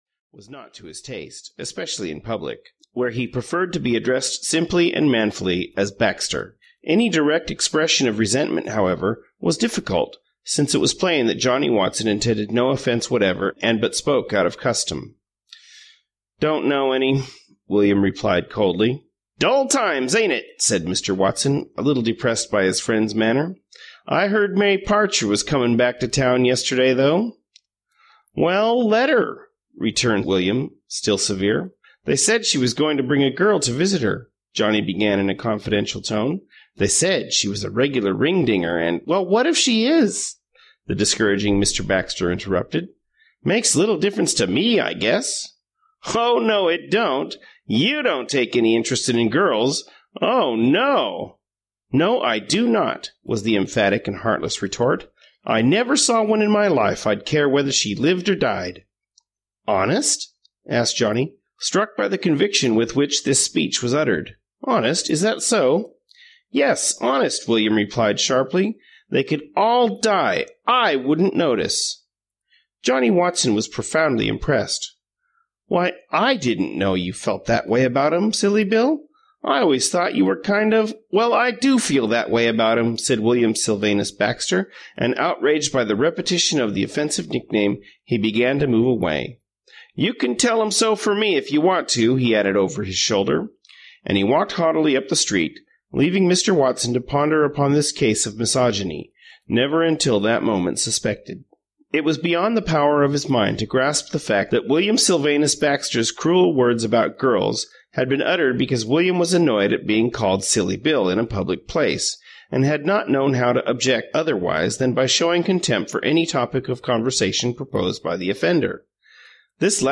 Seventeen (EN) audiokniha
Ukázka z knihy